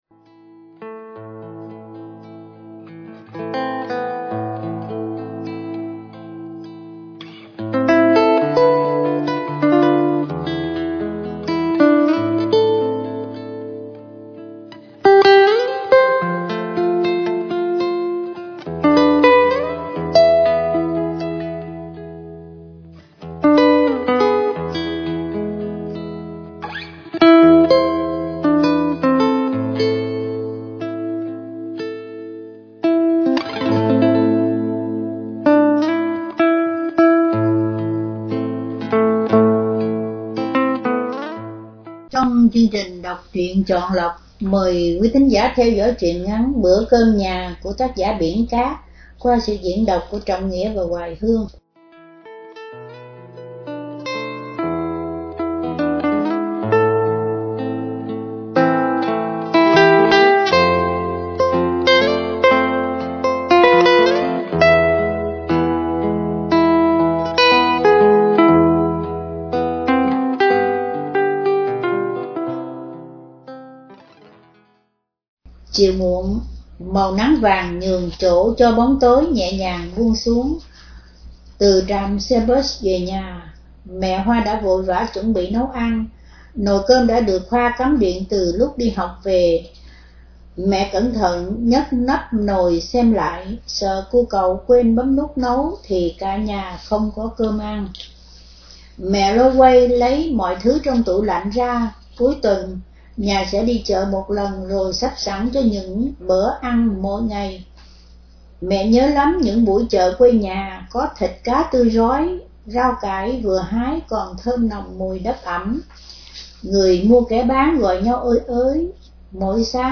Đọc Truyện Chọn Lọc – Truyện ngắn” Bửa Cơm nhà – Biển Cát – Radio Tiếng Nước Tôi San Diego